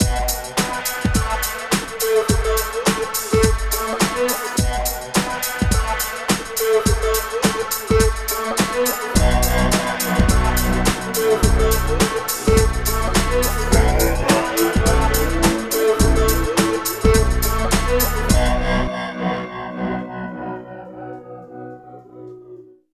18 LOOP   -L.wav